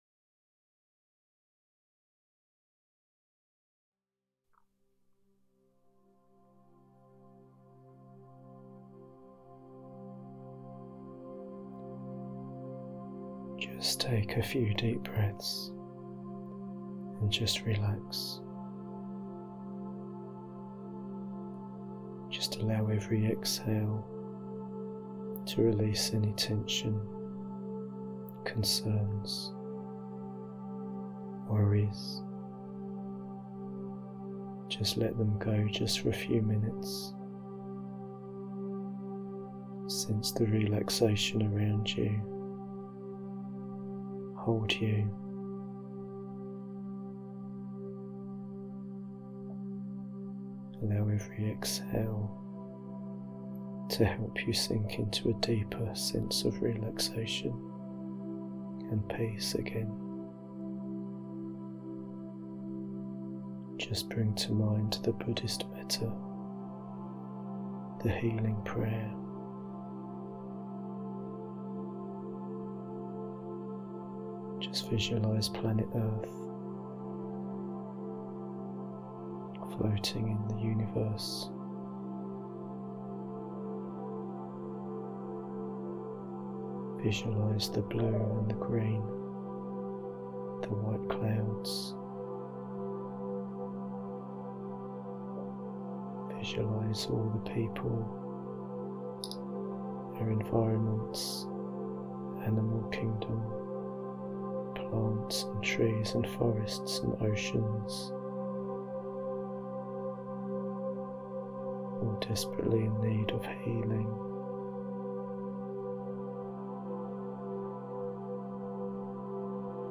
Genre: Meditation.